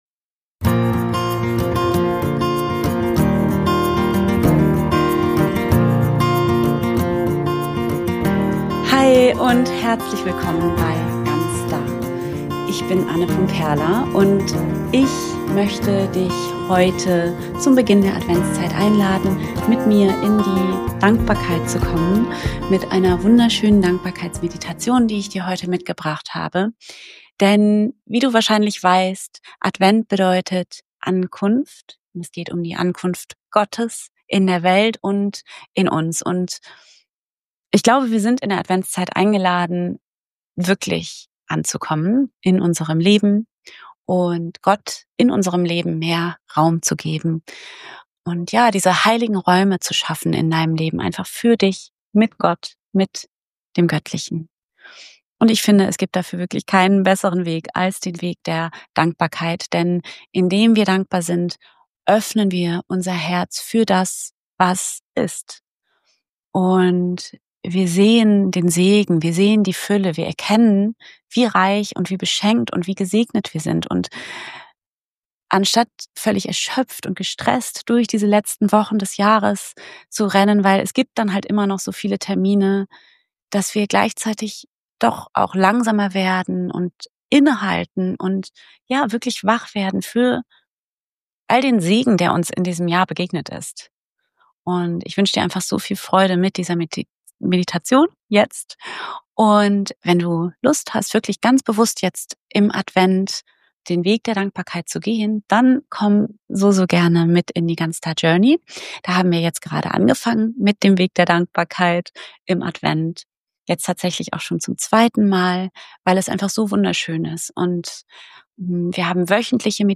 In dieser Folge begleite ich dich in eine stille, warme Adventsmeditation. Sie erinnert dich an die Fülle in deinem Leben, an den Segen, der dich umgibt, und an die Gegenwart Gottes – mitten in deinem Alltag.